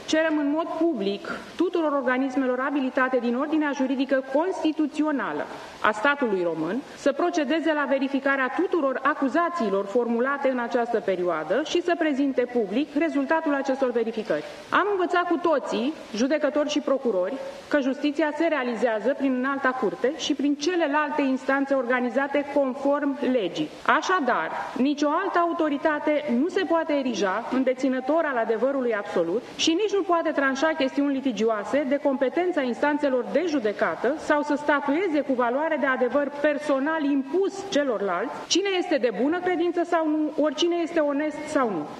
Curtea de Apel București a organizat o conferință de presă, iar conducerea instituției a criticat acuzațiile lansate în documentarul Recorder.
În schimb, Președintele Curții de Apel București, Liana Arsenie, a subliniat că instituția apără independența justiției și combate narativele manipulative.